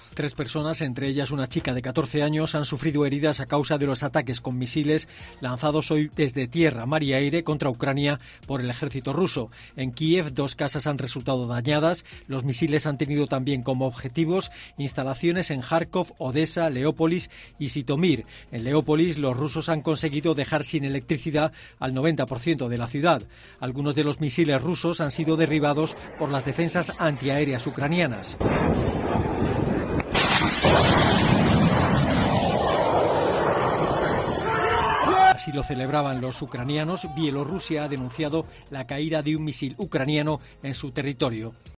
Internacional